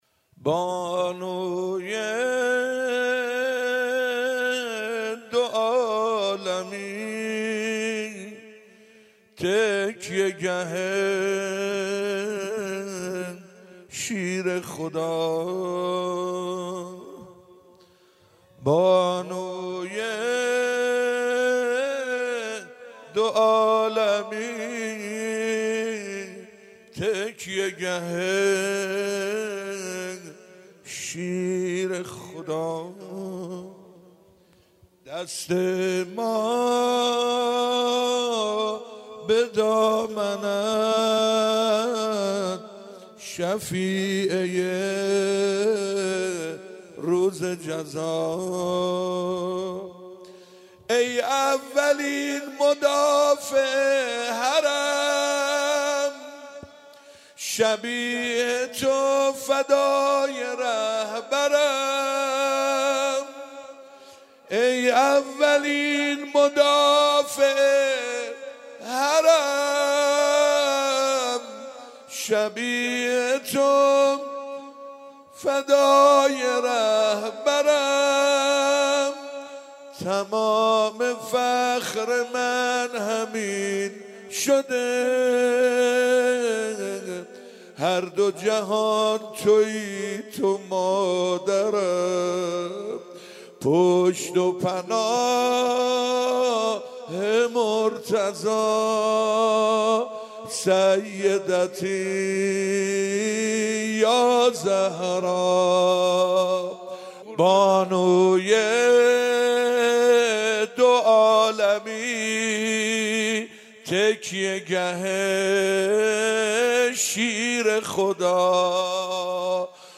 شب اول فاطميه 95 - زمينه و روضه